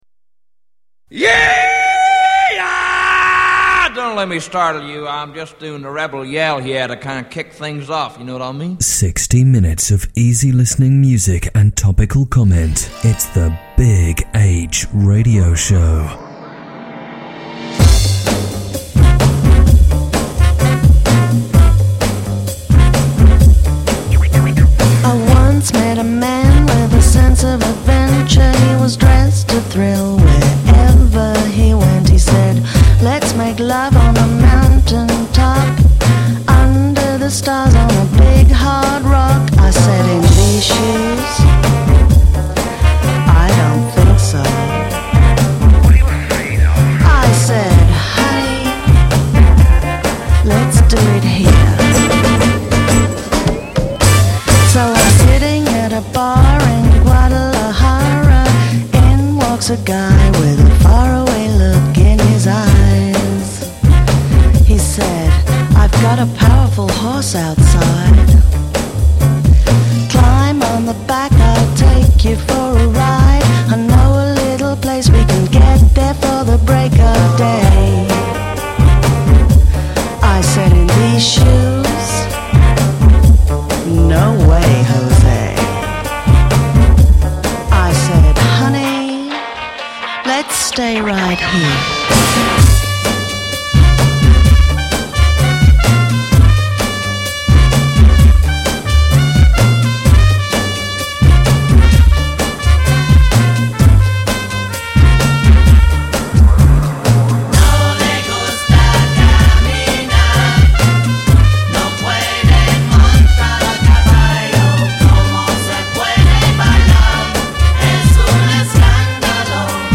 Latest Episode The Big H Radio Show Sampler Download the latest episode Note: in some browsers you may have to wait for the whole file to download before autoplay will launch. The Big H Radio Show Easy listening request music show broadcasting out of Oxford in the UK.